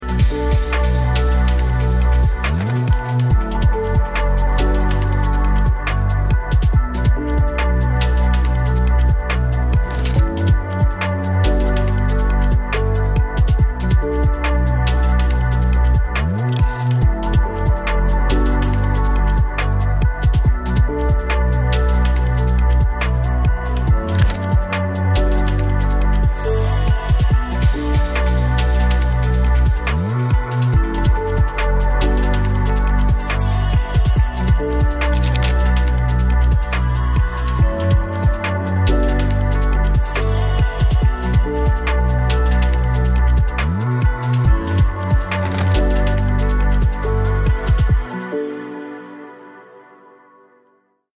DLS Turbo services celebrate the 934.5 endurance racer of the late 1970s. This car, shown in Turbo Racing White and Giallo Segnale is shown here in the Supercar Paddock at the 2025 Goodwood Festival of Speed.